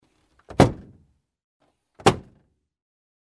Звуки багажника
Звук захлопывания багажника седана